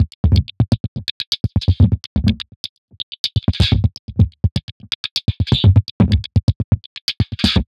tx_perc_125_slipjack.wav